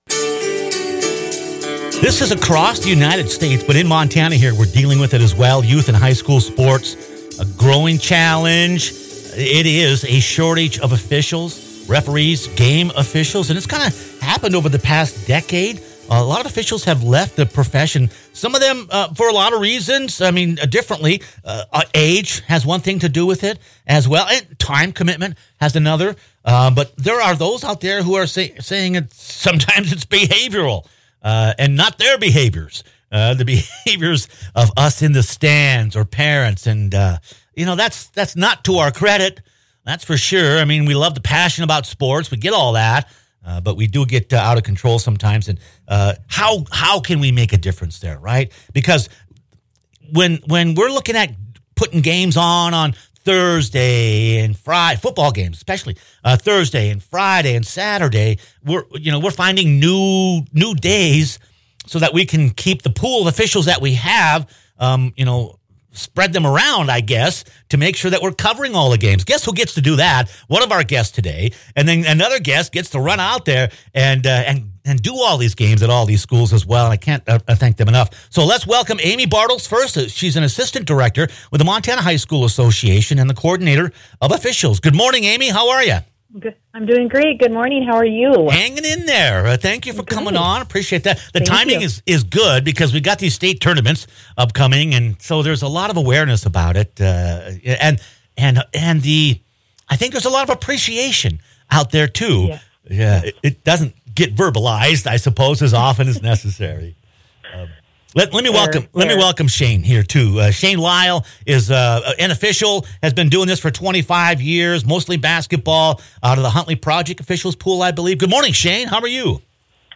Like much of the country, Montana is facing a shortage of sports officials. Click on the podcast as we talk with officials and the Montana High School Association about the challenges, the rewards, and how younger students are encouraged to part of the solution.